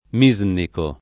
DOP: Dizionario di Ortografia e Pronunzia della lingua italiana
vai all'elenco alfabetico delle voci ingrandisci il carattere 100% rimpicciolisci il carattere stampa invia tramite posta elettronica codividi su Facebook misnico [ m &@ niko ] agg.; pl. m. ‑ci — della Misnà